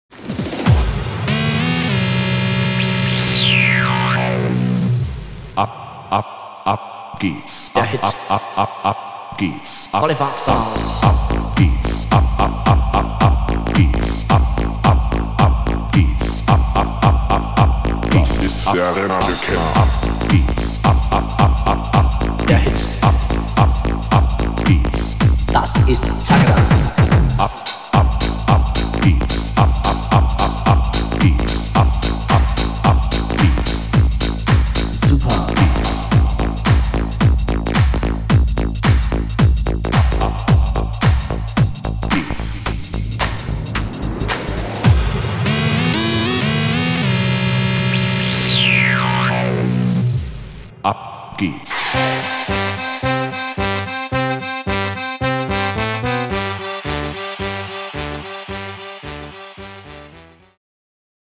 originalen Stimme (Vocals)